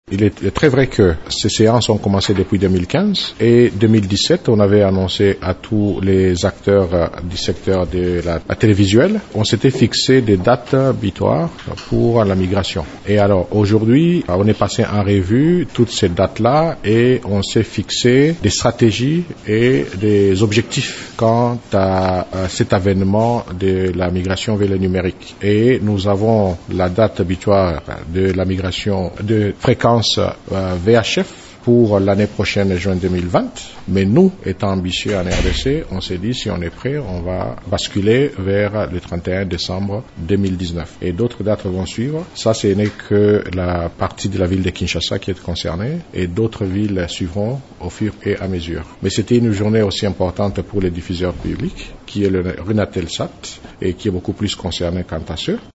Le président intérimaire du collège des conseillers de l’ARPTC Odon Kasindi Maotela, donne quelques orientations de ce processus de migration.